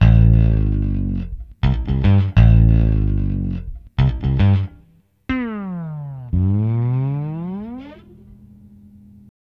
Ala Brzl 1 Bass-A.wav